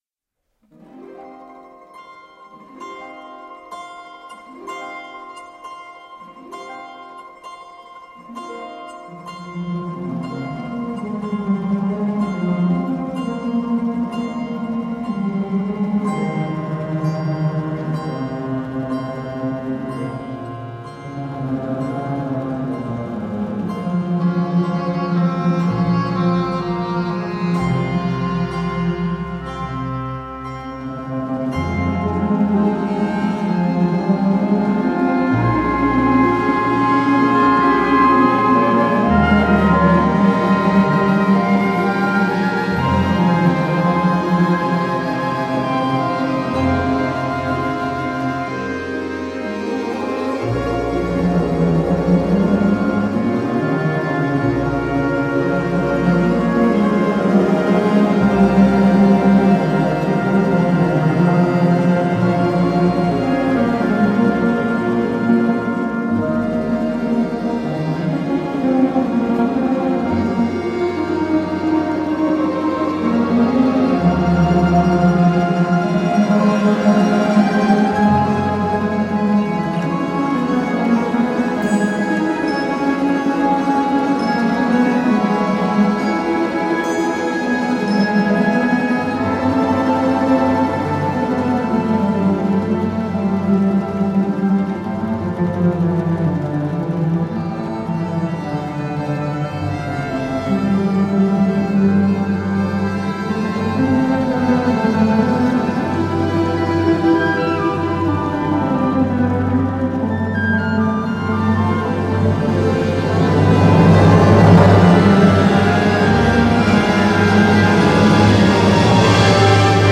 альт